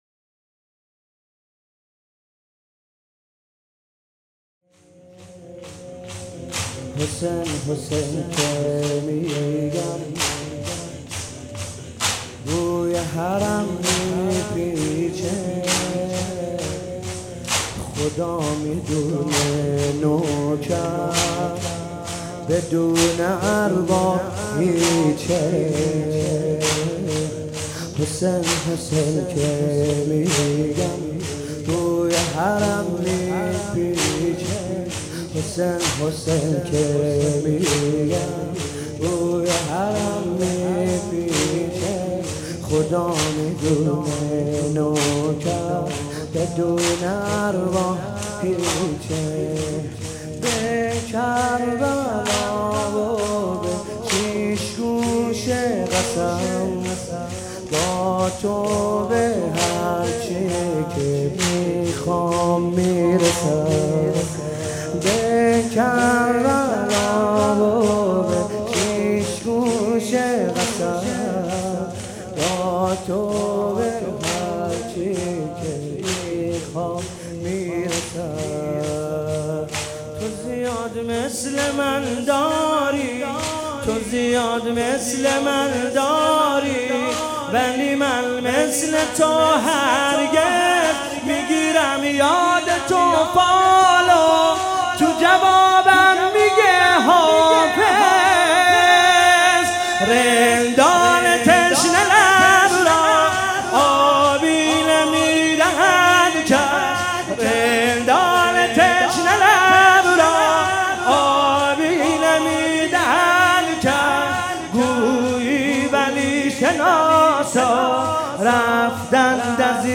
خیمه گاه - حضرت علی اکبر(ع) - محرم 97 شب هشتم - حسین طاهری - شور - حسین حسین که میگم بوی حرم میپیچه